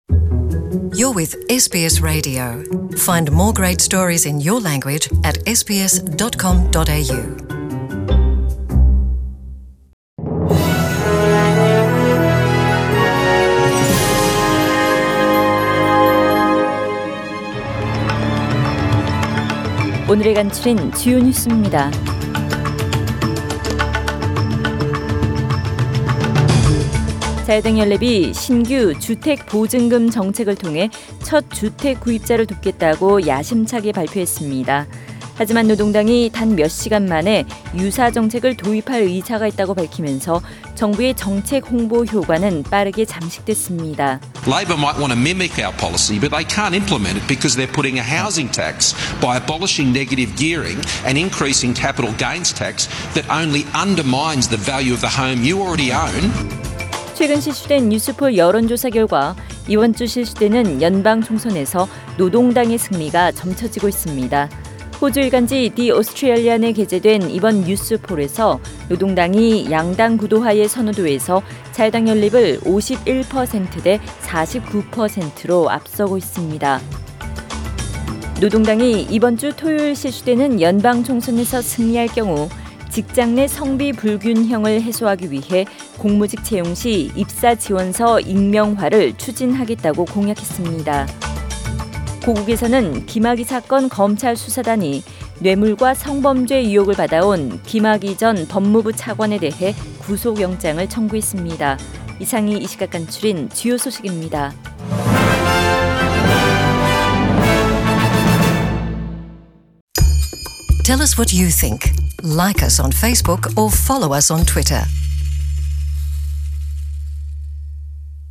SBS 한국어 뉴스 간추린 주요 소식 – 5월 13일 월요일